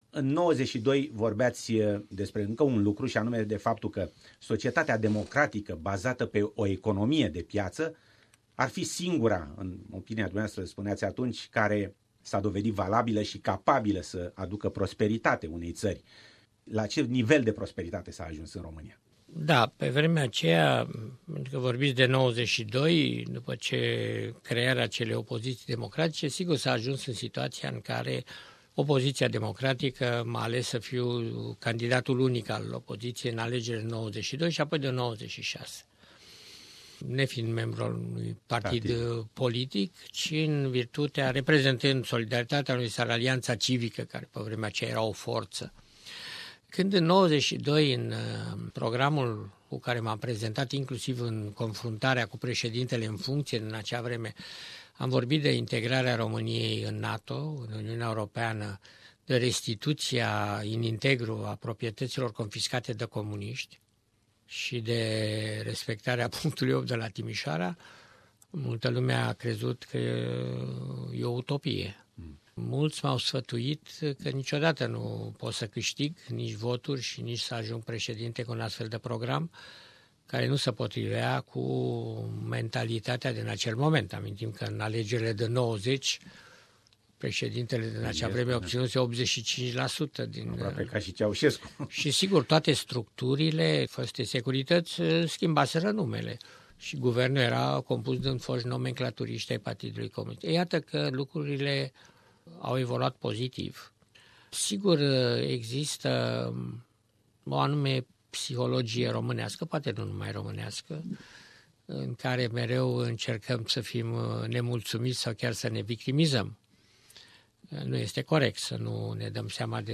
Interviu cu fostul Presedinte al Romaniei, Emil Constantinescu - pt.2
Interviu cu fostul Presedinte al Romaniei, Emil Constantinescu - pt.2 - 9 iunie 2017, Radio SBS, Melbourne, Australia